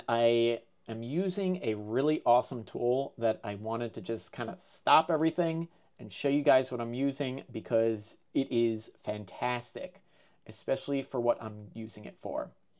Bad Original Audio